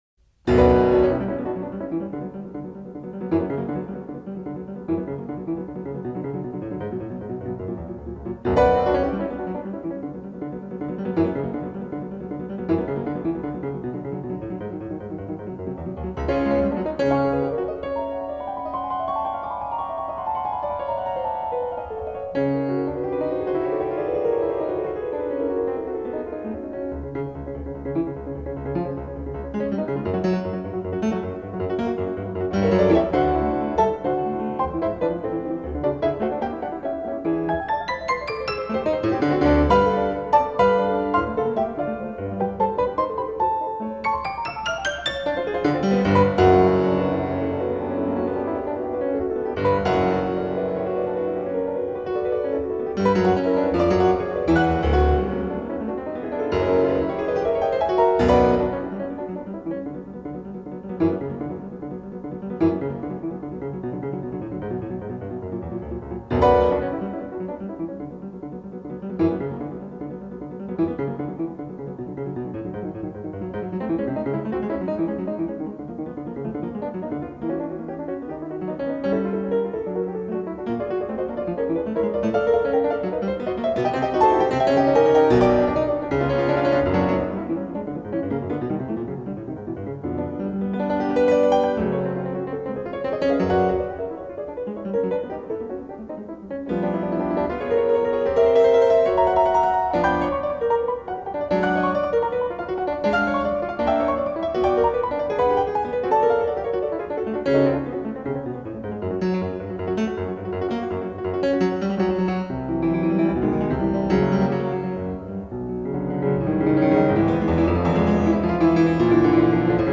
RNS2 Sonate Piano P. Dukas (Vivement - Avec légèreté)